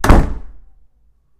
doors_door_close.ogg